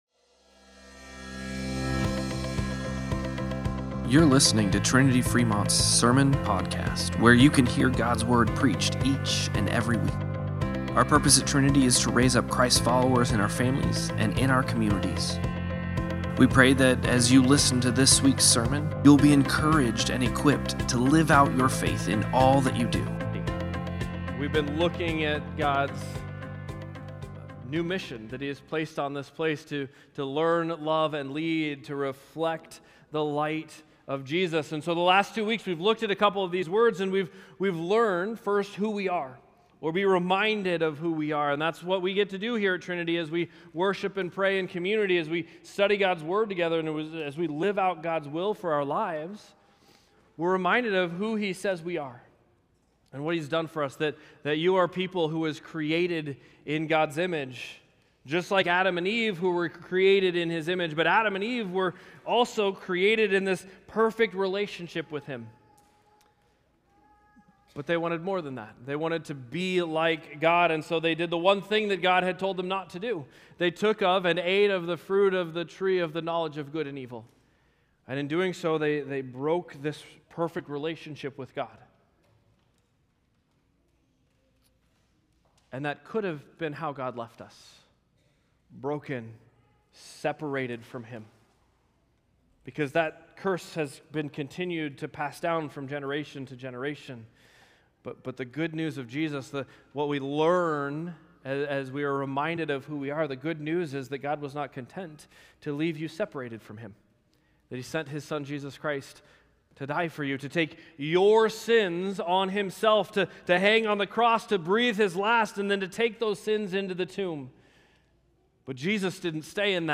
09-21-Sermon-po.mp3